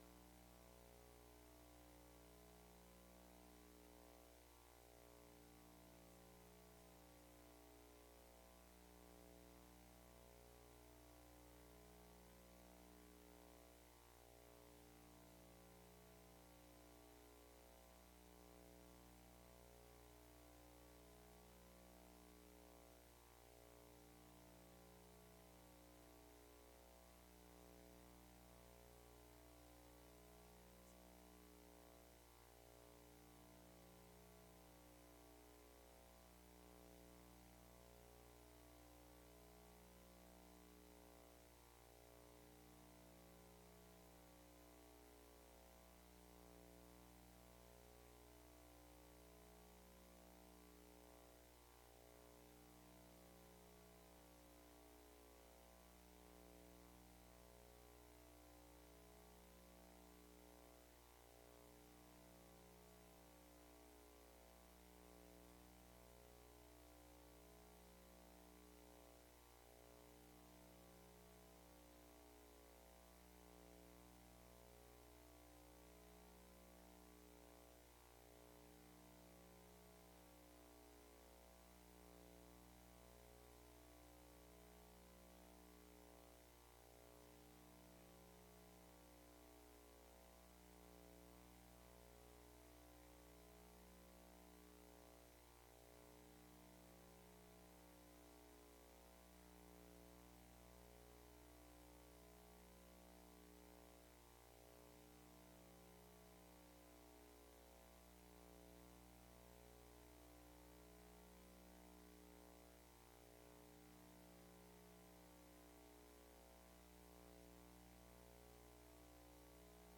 August-3-2025-Morning-Service.mp3